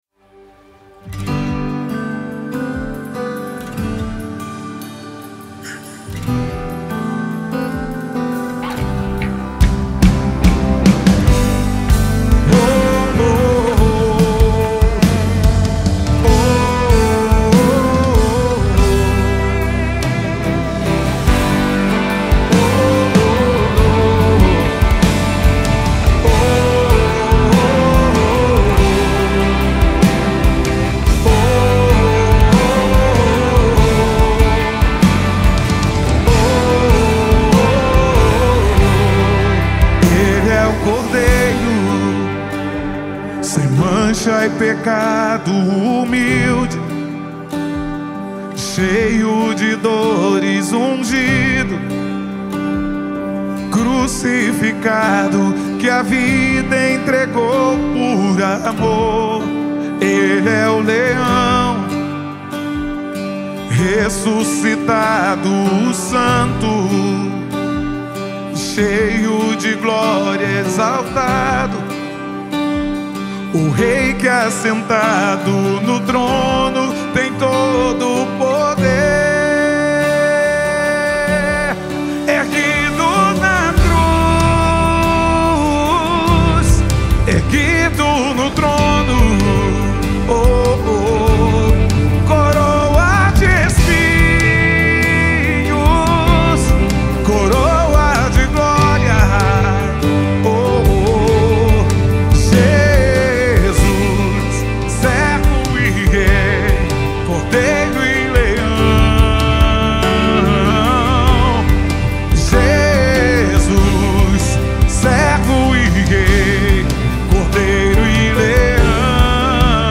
2024 single